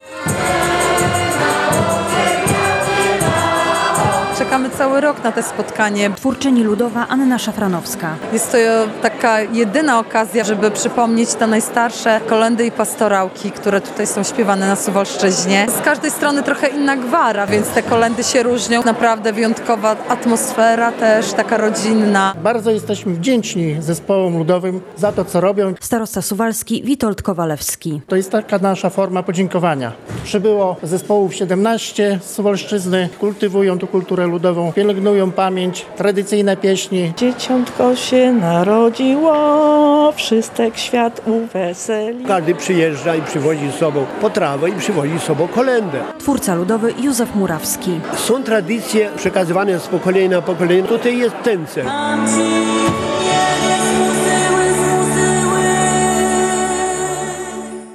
To podziękowanie artystom ludowym za ich wkład w ochronę dziedzictwa kulturowego Suwalszczyzny, ale też okazja do zaśpiewania najstarszych kolęd i pastorałek. Po raz 31 do wspólnej kolacji podczas tradycyjnej Wigilii Chłopskiej zasiadły zespoły ludowe z powiatu suwalskiego.